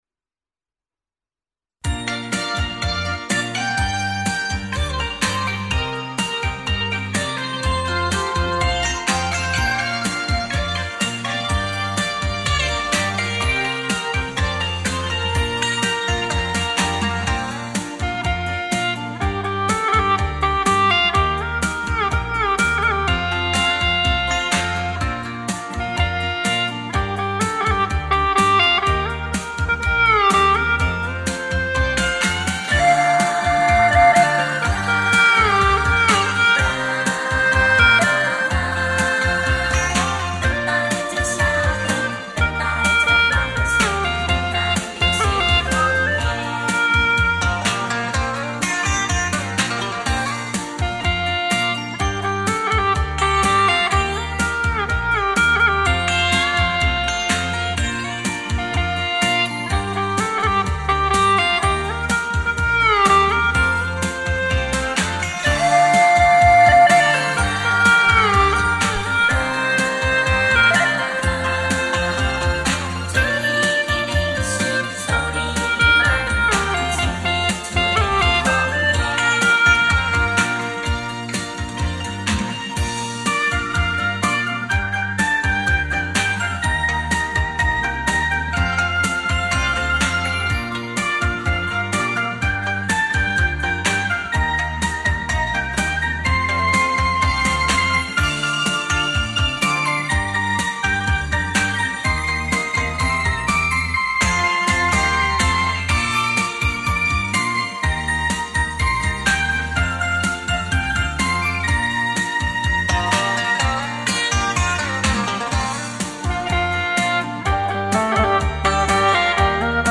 调式 : 降B
朗朗上口的旋律